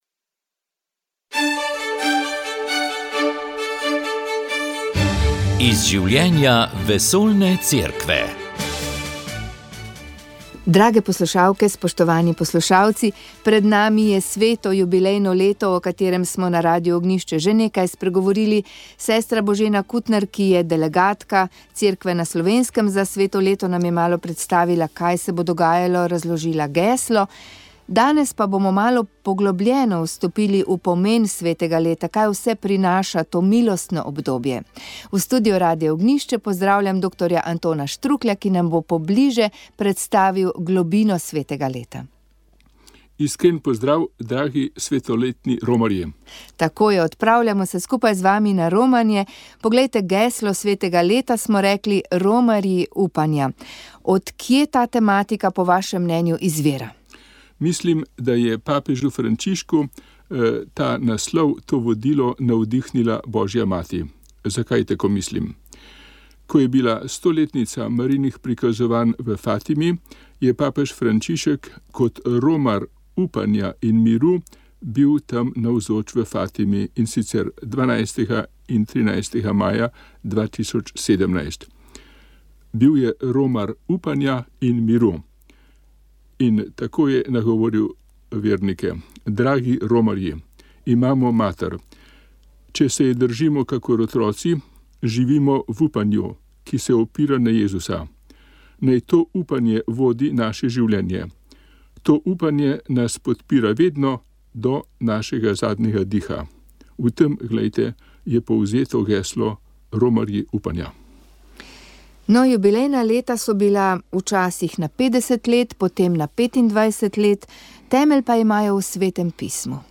pogovor